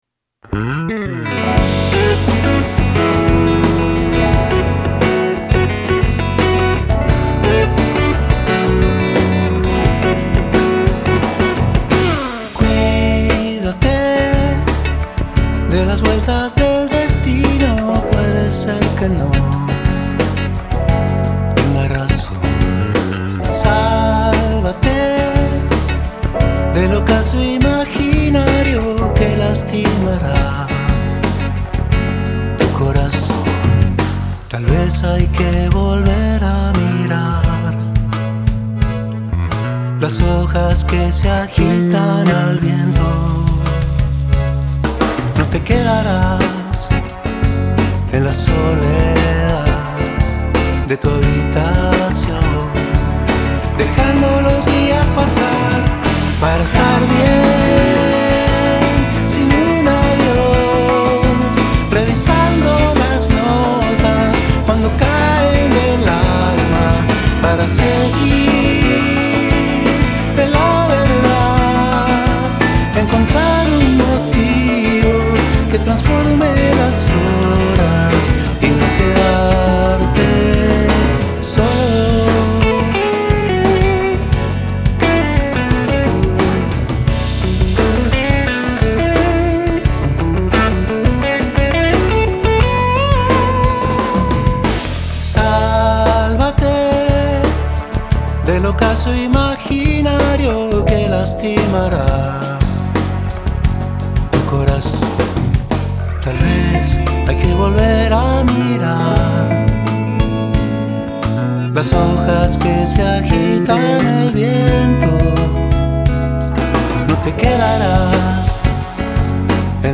POP FOLK LATINO
BALADA